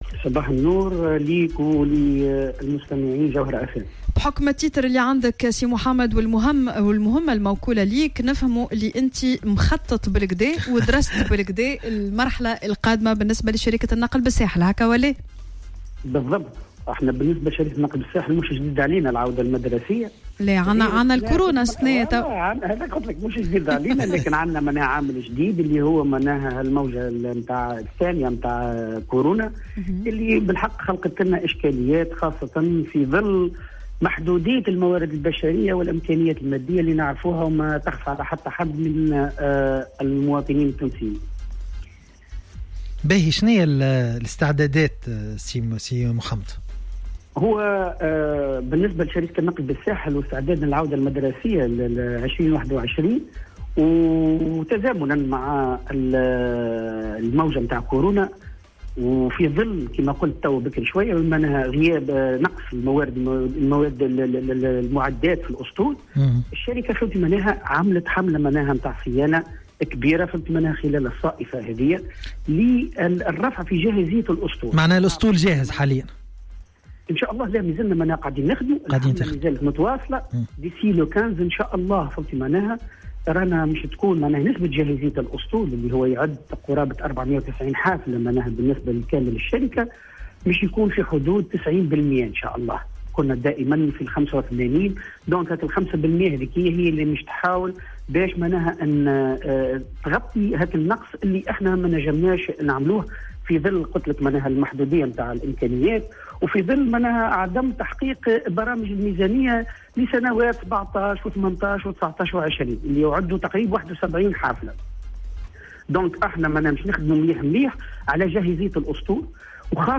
وأضاف في مداخلة له اليوم على "الجوهرة أف أم" أن الشركة قد اتخذت جملة من الإجراءات استعدادا للعودة المدرسية والجامعية خاصة في ظل محدودية الموارد البشرية والامكانيات المادية، من ذلك القيام بحملة صيانة كبيرة للمعدات للرفع من جاهزية الأسطول.